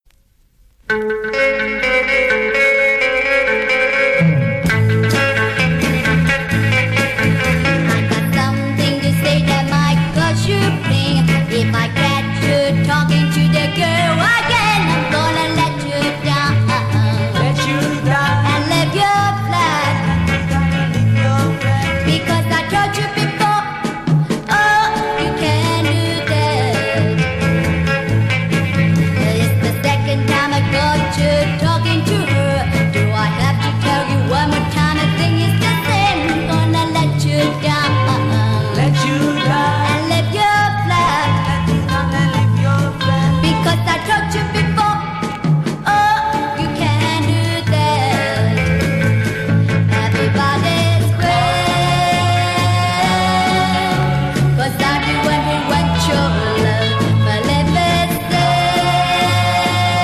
Genre: Rock, Pop
Style: Pop Rock, Cantopop, Hokkien Pop, Mandopop